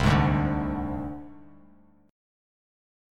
Csus2#5 chord